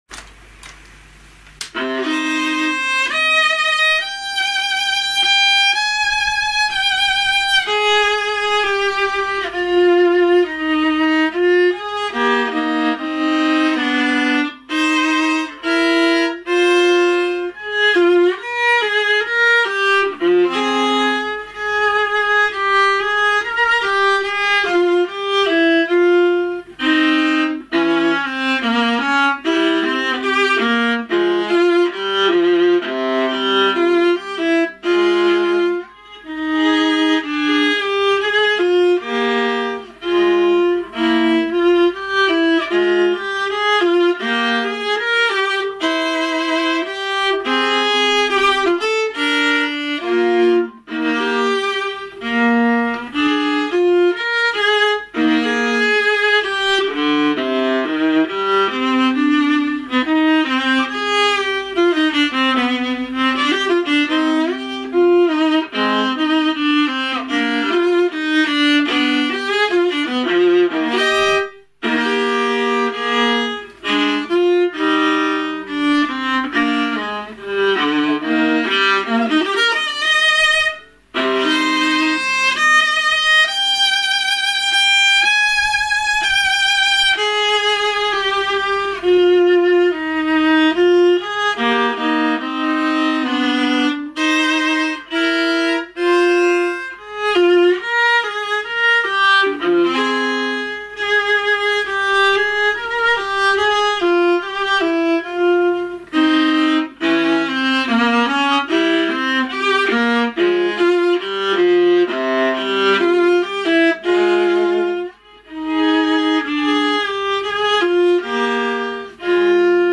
this virtuosic, multi-faceted, five-movement work